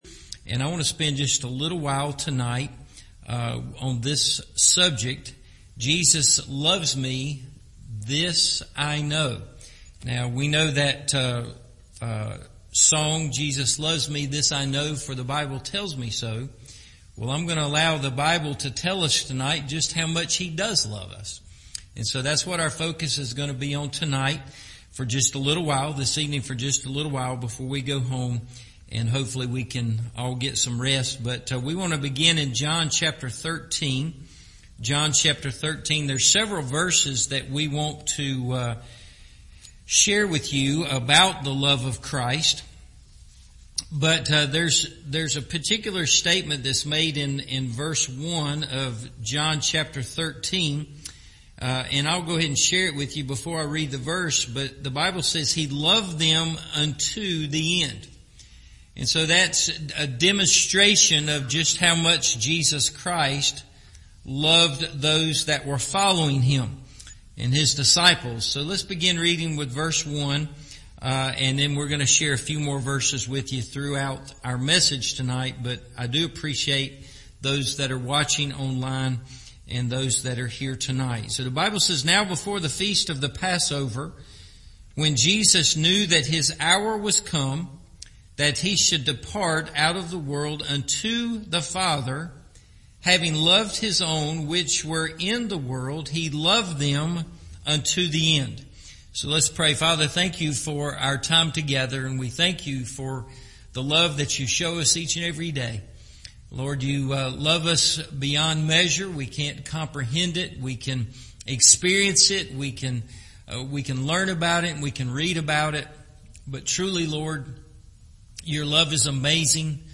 Jesus Loves Me, This I Know – Evening Service